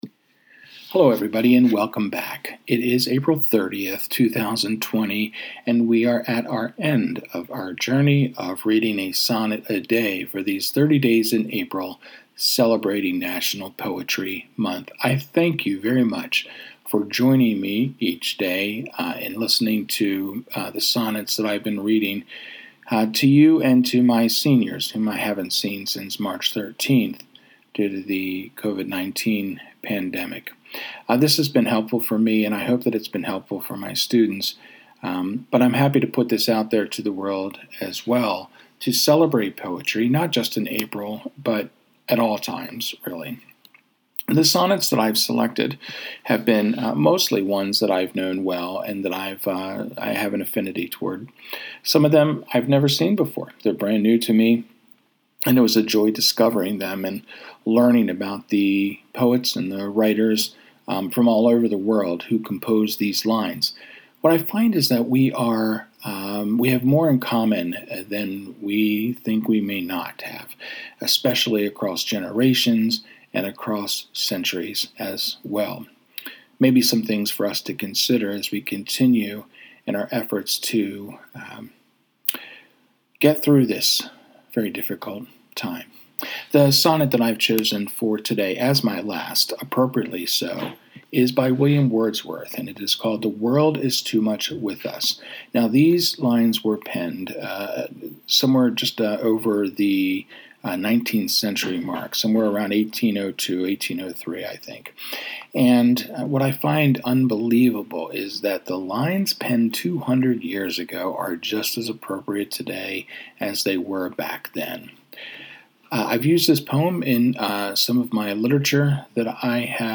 As I say in the preface of the reading, I’ve been so honored to share these sonnets with you.